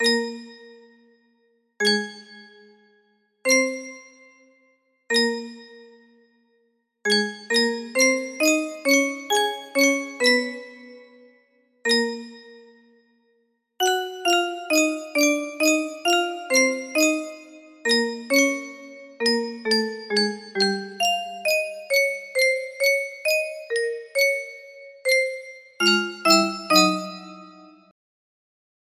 Full range 60
not super good